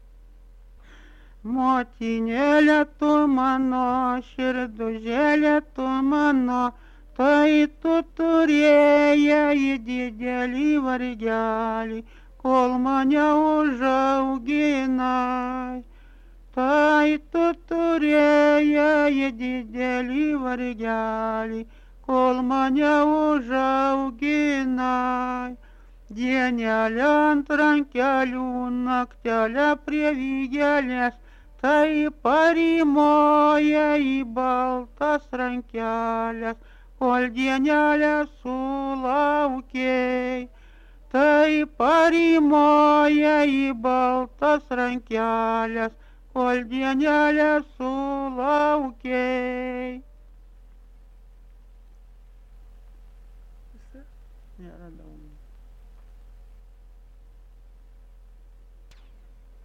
Polka
šokis